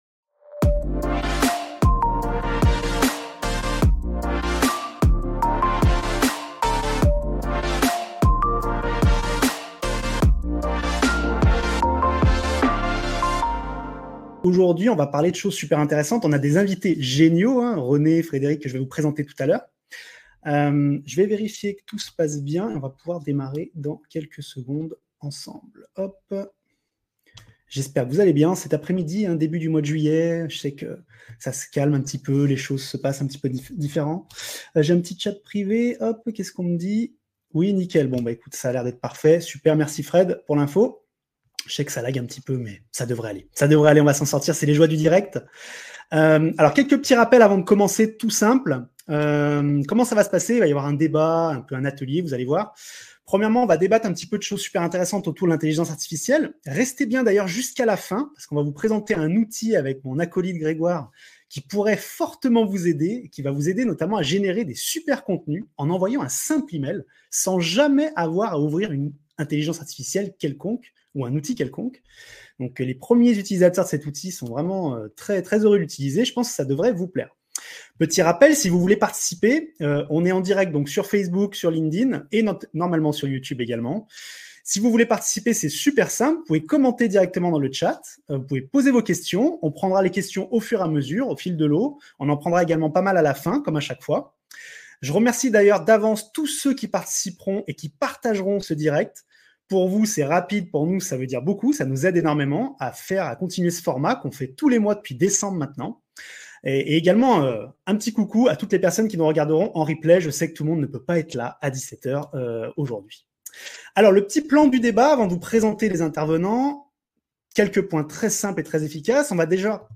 L'impact de l'IA dans la relation client et l'Ecommerce - Table Ronde